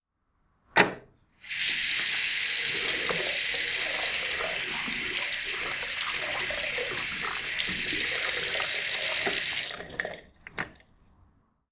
tap-water-1.wav